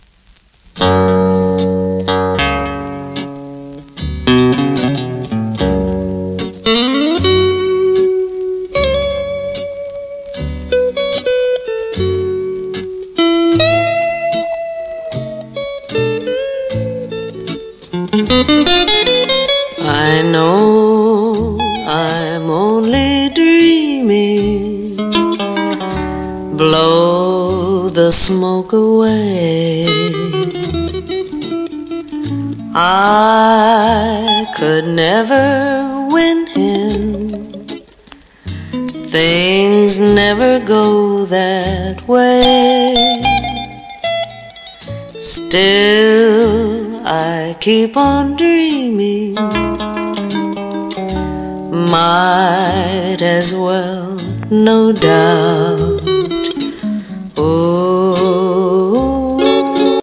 1956年頃の録音盤。